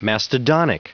Prononciation du mot mastodonic en anglais (fichier audio)
Prononciation du mot : mastodonic